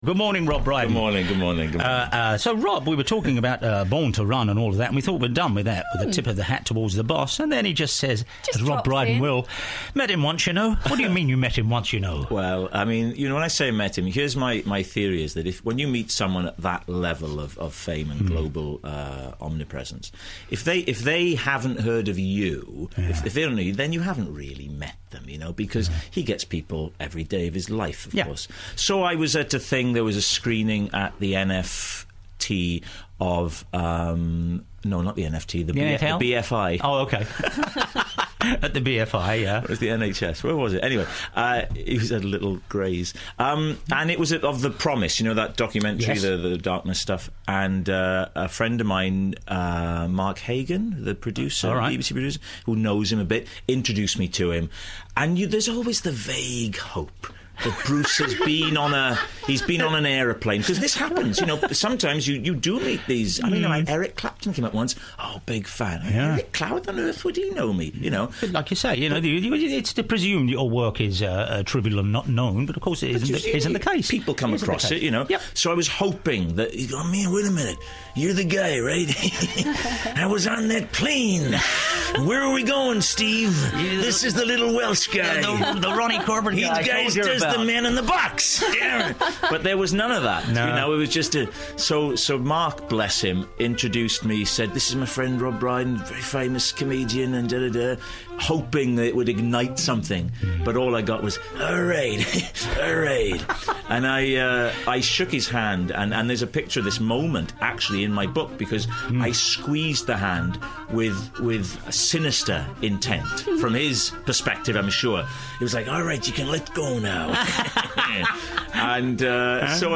Welsh comic, writer, singer, actor and impressionist Rob Brydon popped into the Danny Baker Saturday show on 5Live. The result was a very entertaining chat about his hero-worship of The Boss - Bruce Springsteen.